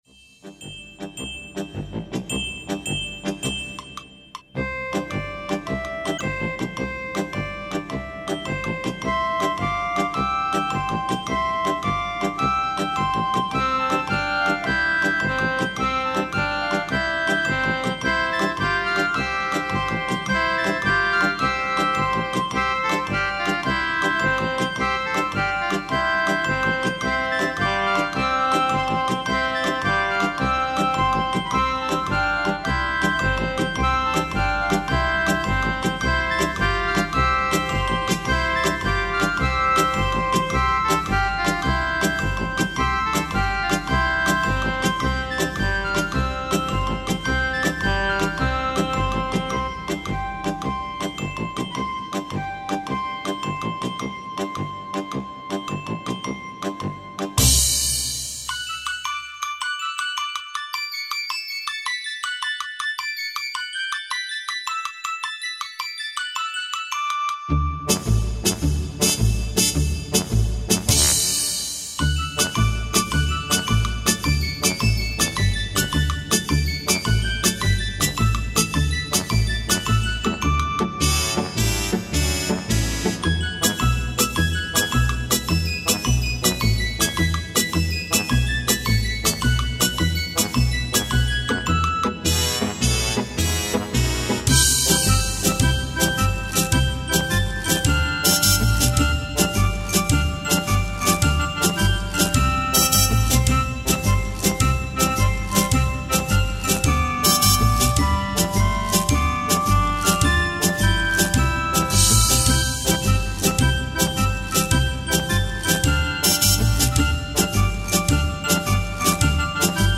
traditional nursery rhymes and popular children's